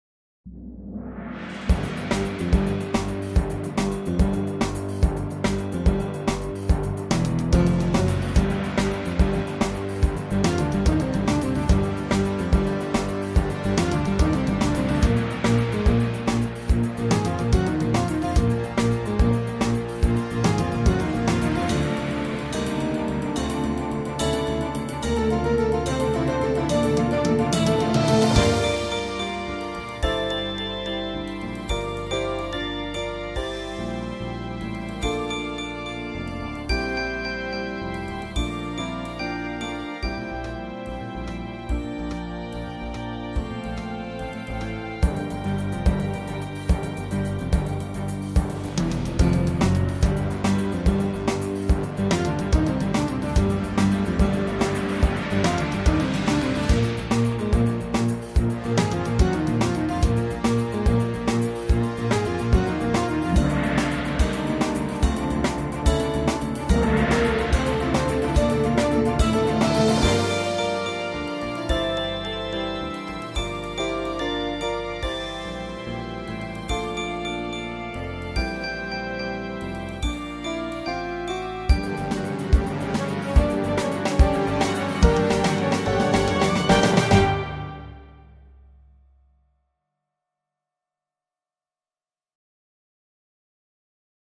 11 Airport! (Violin Backing Track